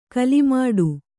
♪ kalimāḍu